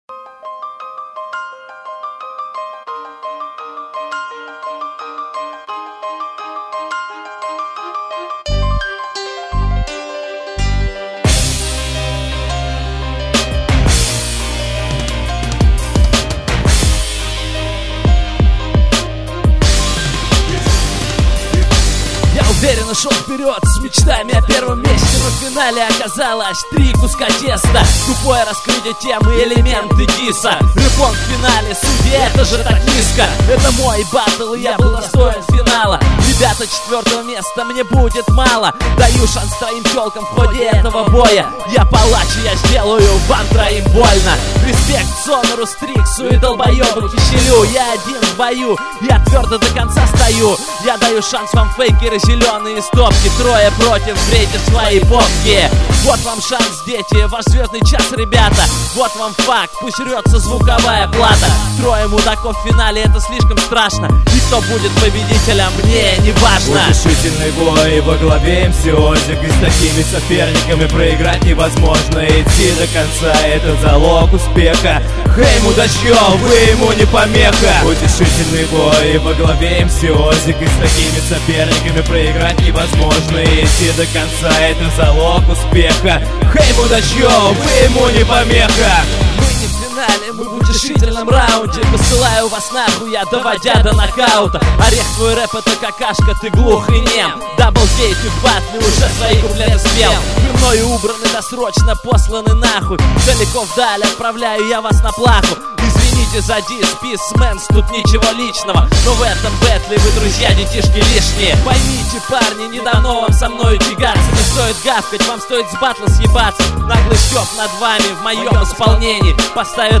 mp3,6633k] Рэп